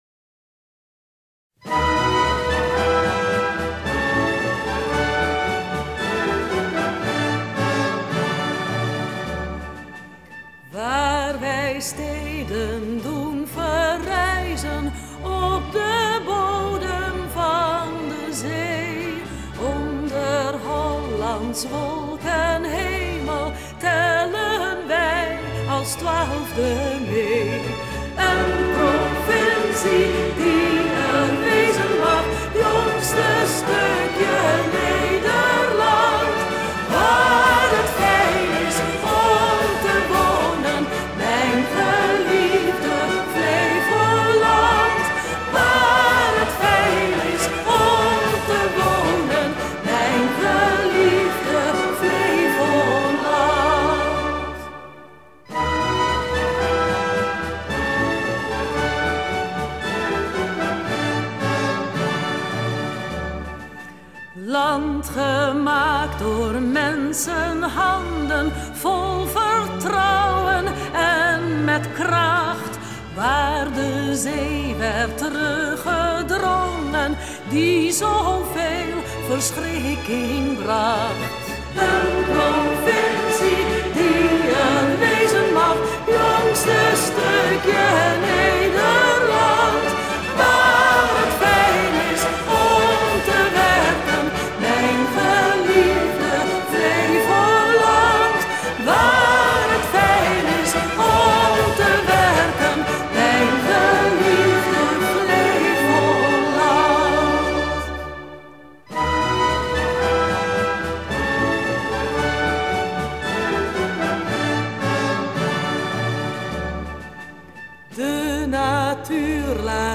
Vocaal: